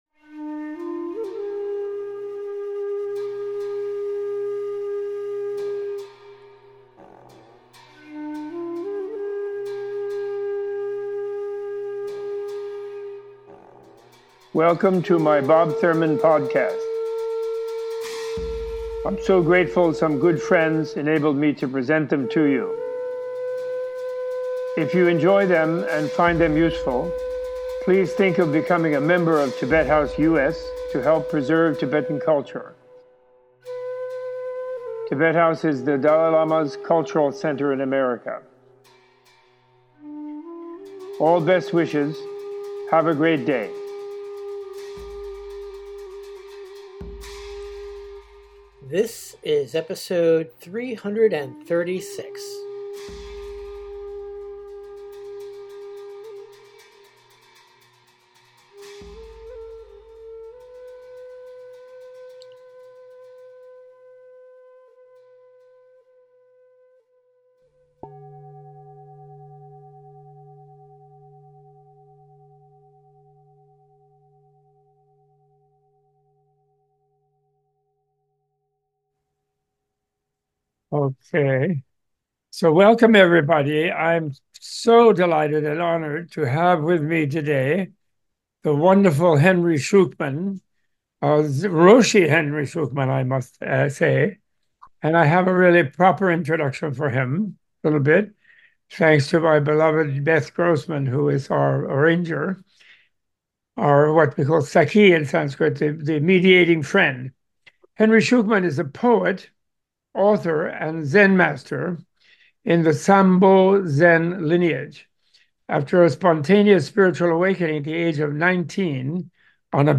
A Tibet House US Menla Conversation